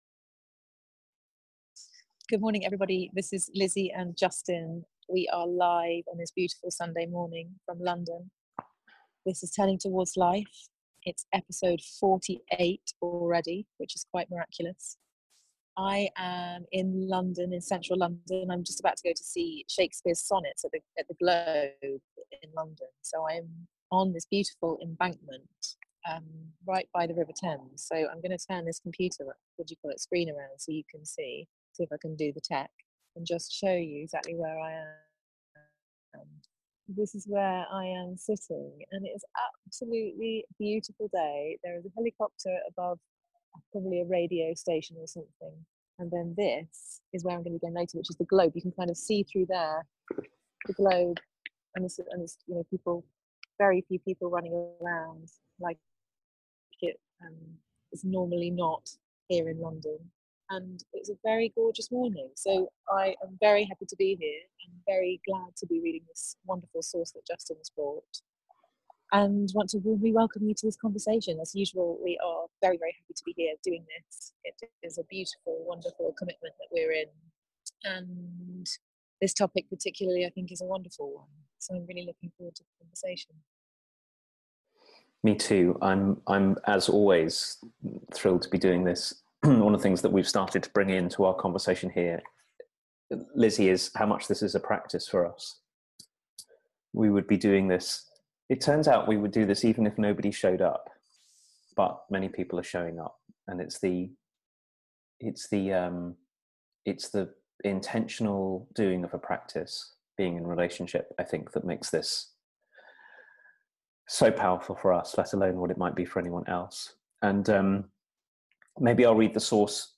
Reclaiming our aliveness and the grip of our addictions. A conversation about paying attention to our lives and the felt-sensation of our bodies.
Our conversation opens with an excerpt from Christine Caldwell’s book ‘Getting Our Bodies Back‘.